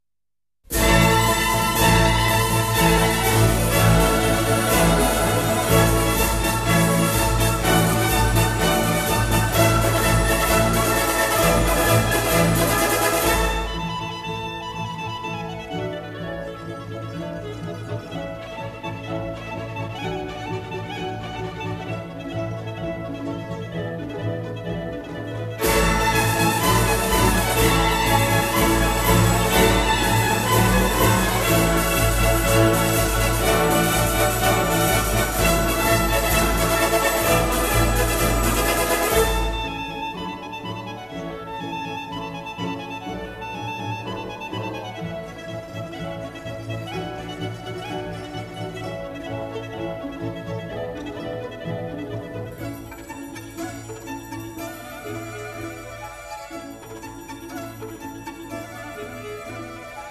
這個「年代古老」的錄音的確讓每個人驚豔，
它不但呈現出明確的左右關係，也呈現了明確的前後關係。